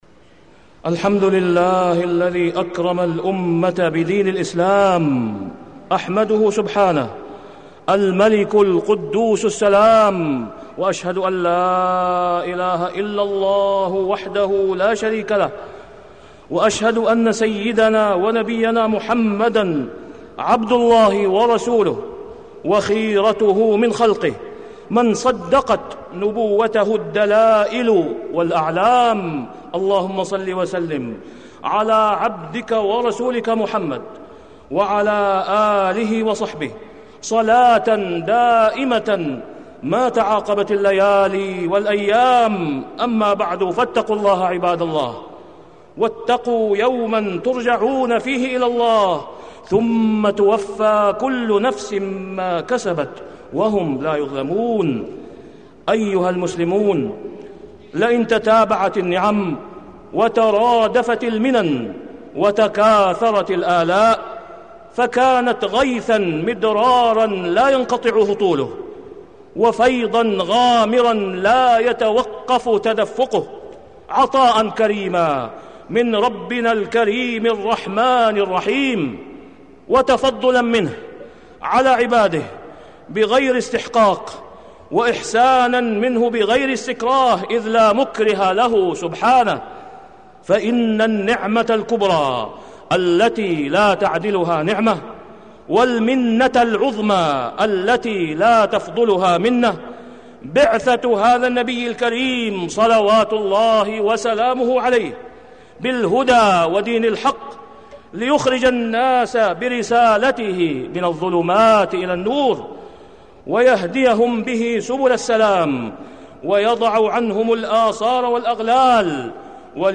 تاريخ النشر ٢٩ شعبان ١٤٢٧ هـ المكان: المسجد الحرام الشيخ: فضيلة الشيخ د. أسامة بن عبدالله خياط فضيلة الشيخ د. أسامة بن عبدالله خياط شمس الرسالة المحمدية